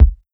Kicks
KICK_STAB_N_TWIST.wav